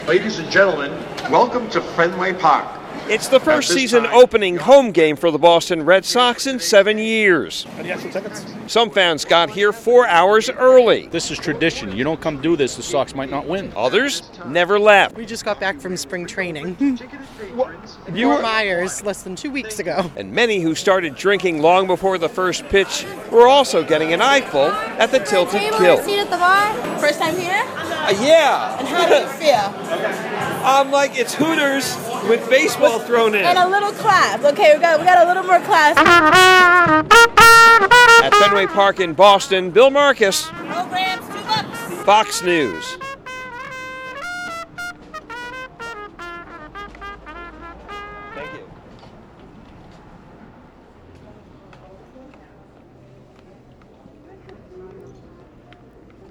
REPORTS FROM FENWAY PARK IN BOSTON: